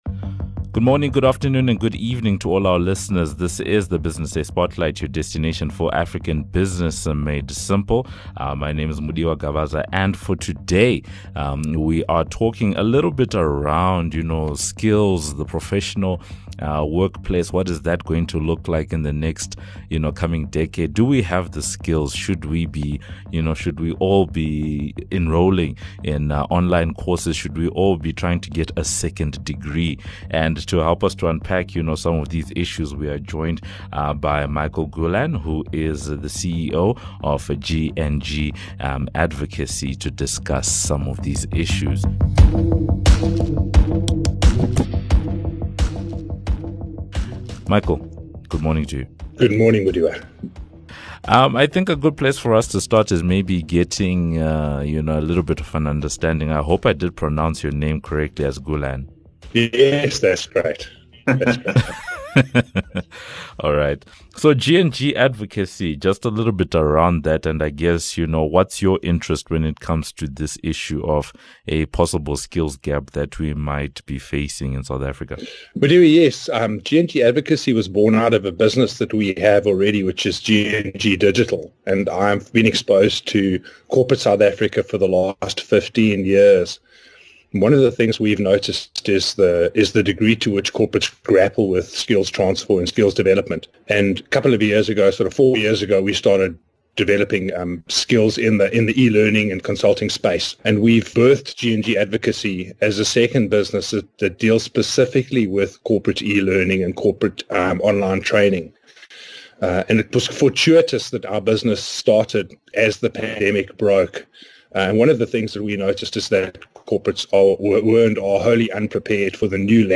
The discussion focuses on the important of continuous learning by employees in corporate SA; the responsibility of companies in ensuring that their people have access to learning tools; technology as enabler for further learning; and the challenges that people face when it comes to putting together an effective learning program for staff.